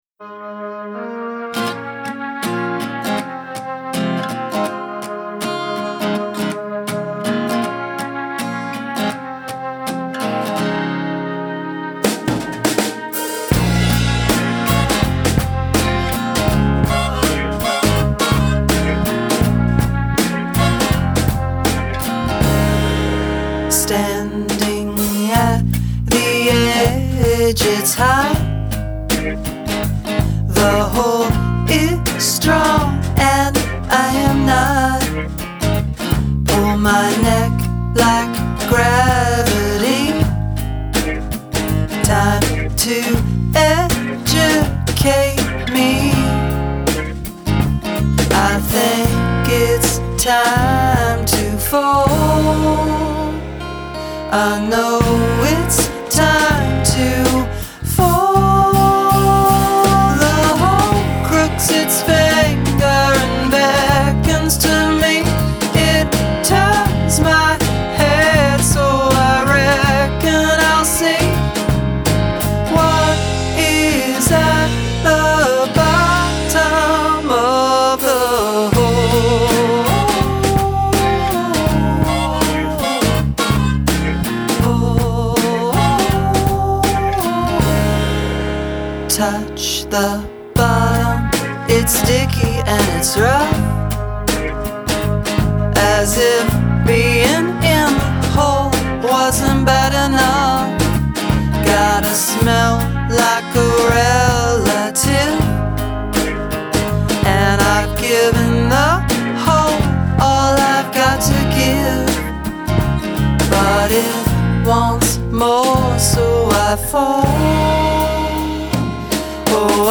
A thoughtful and introspective Americana track